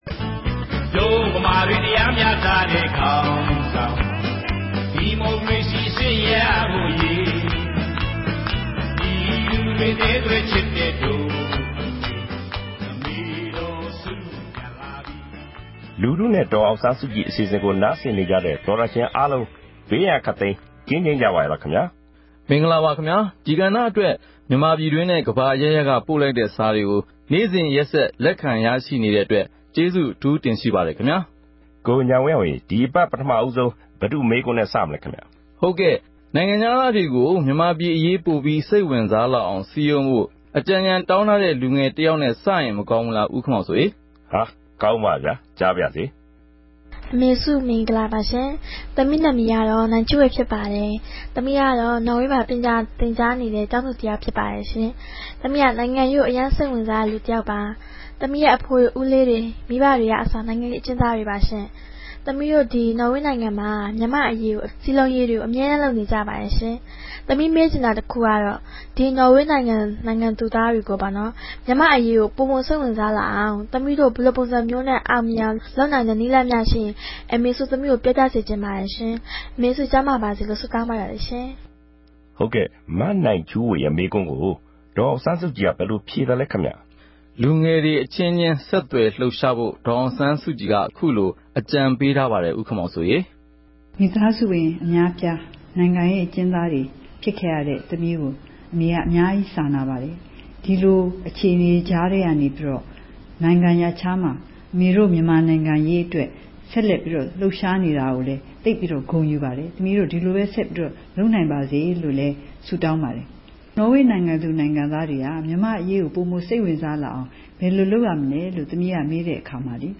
ဒီ အစီအစဉ်ကနေ ပြည်သူတွေ သိချင်တဲ့ မေးခွန်းတွေကို ဒေါ်အောင်ဆန်းစုကြည် ကိုယ်တိုင် ဖြေကြားပေးမှာ ဖြစ်ပါတယ်။
အဲဒီ တယ်လီဖုန်း နံပါတ်ကို RFA က ဆက်သွယ်ပြီး ကာယကံရှင်ရဲ့ မေးမြန်းစကားတွေကို အသံဖမ်းယူကာ ဒေါ်အောင်ဆန်းစုကြည်ရဲ့ ဖြေကြားချက်နဲ့အတူ ထုတ်လွှင့်ပေးမှာ ဖြစ်ပါတယ်။